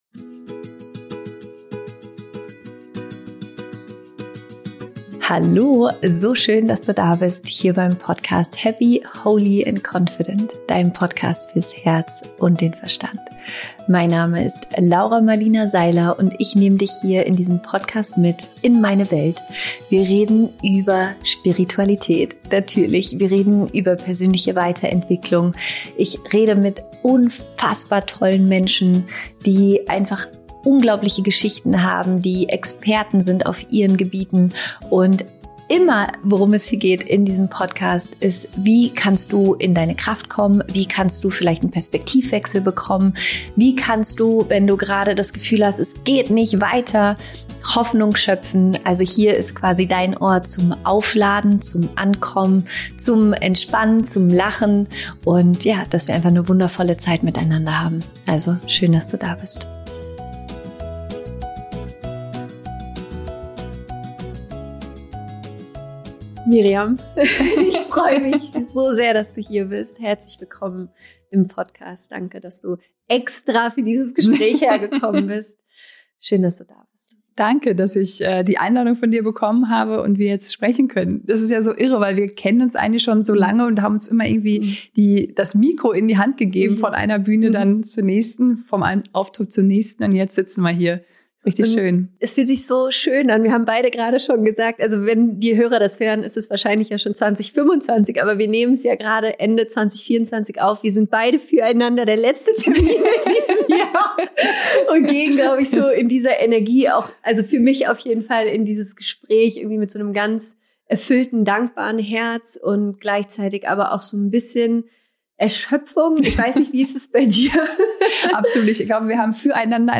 Im Gespräch mit Miriam Höller erfährst du, …